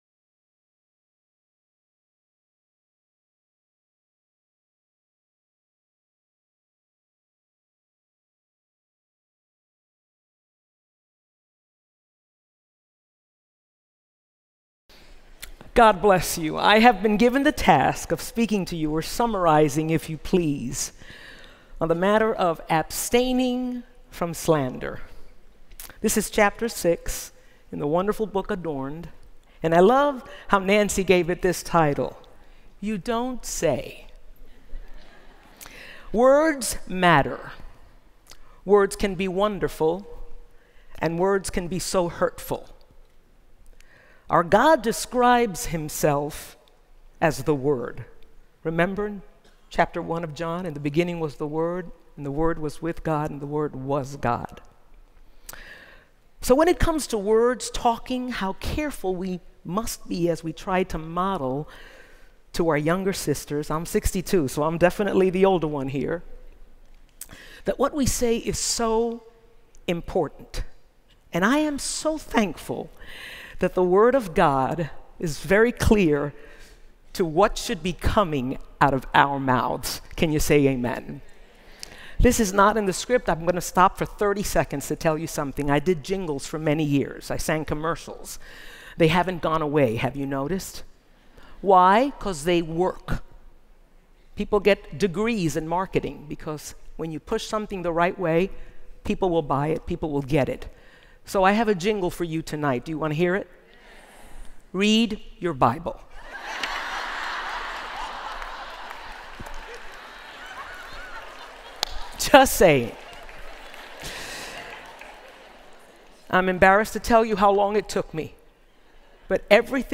Message 6: You Don't Say